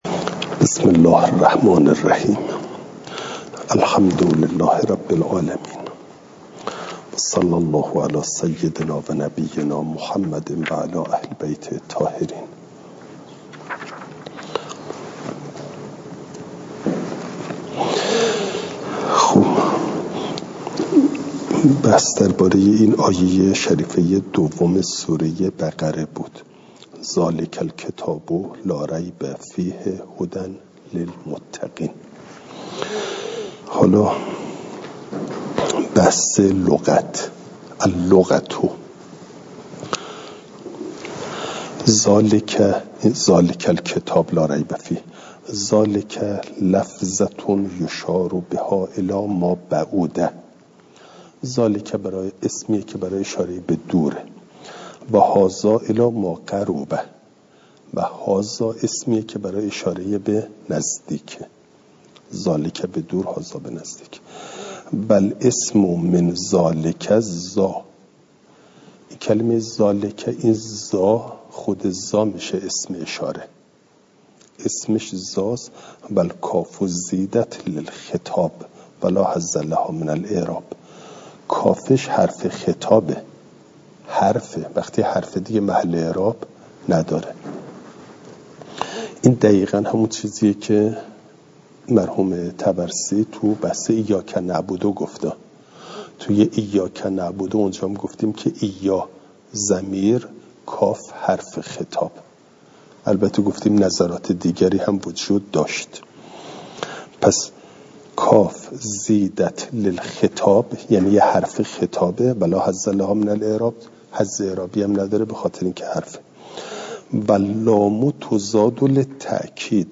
فایل صوتی جلسه شانزدهم درس تفسیر مجمع البیان